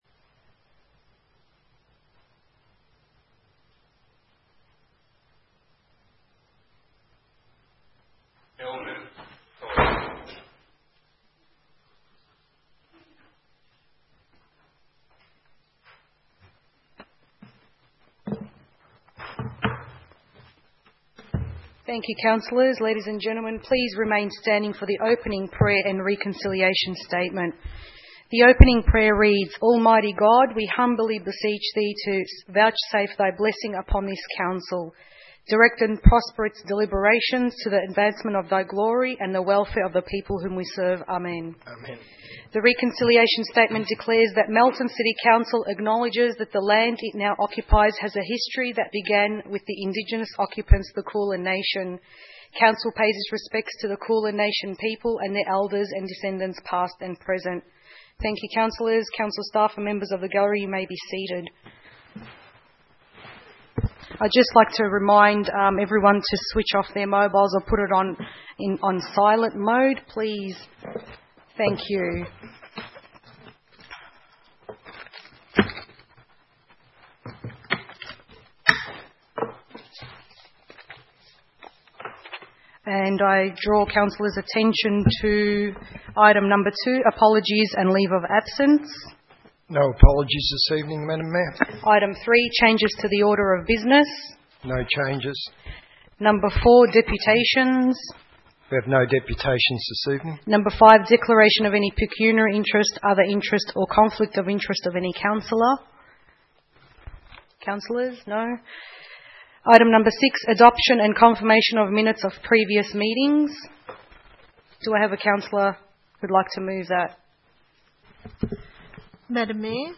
7 March 2016 - Ordinary Council Meeting